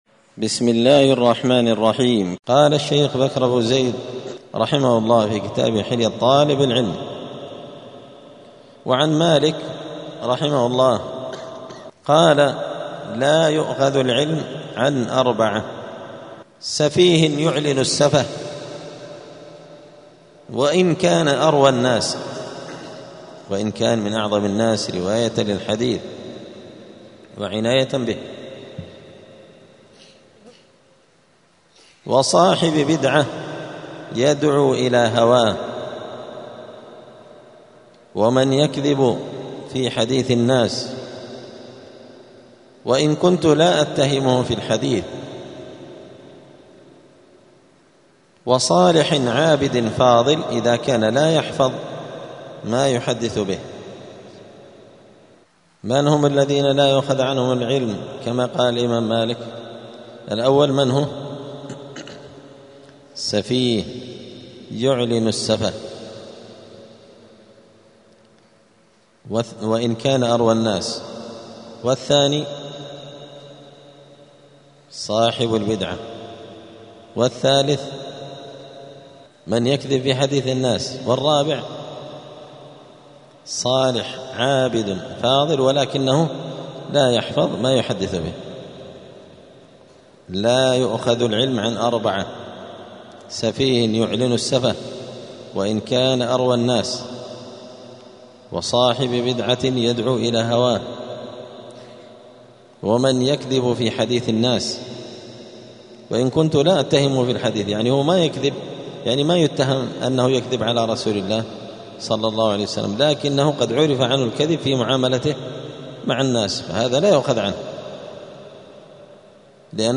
*الدرس الأربعون (40) {فصل التلقي عن المبتدع ﻻ ﻳﺆﺧﺬ اﻟﻌﻠﻢ ﻋﻦ ﺃﺭﺑﻌﺔ}*
الأحد 8 ربيع الأول 1447 هــــ | الدروس، حلية طالب العلم، دروس الآداب | شارك بتعليقك | 4 المشاهدات